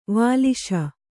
♪ vāliśa